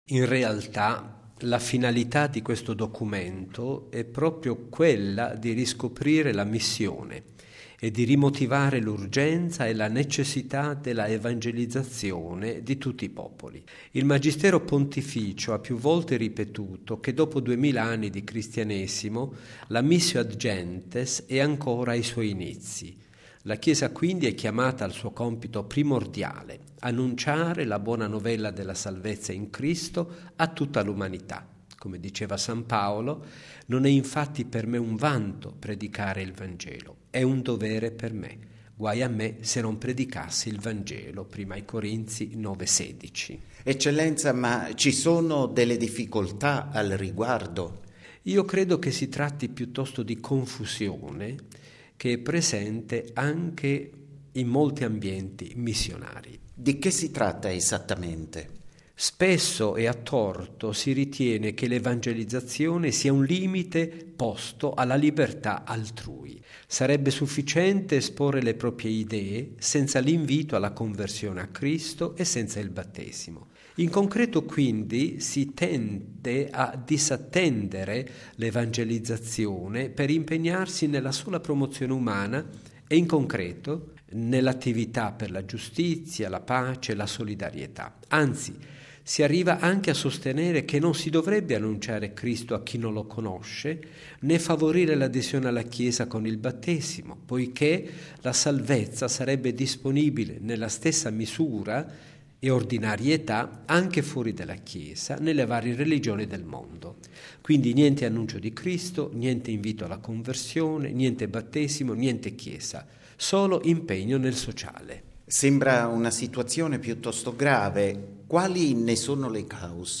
Sugli obiettivi della Nota della Congregazione per la Dottrina della Fede ascoltiamo l’arcivescovo Angelo Amato, segretario del dicastero